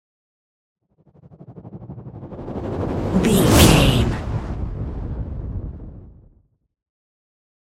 Whoosh to hit trailer long
Sound Effects
Atonal
intense
tension
the trailer effect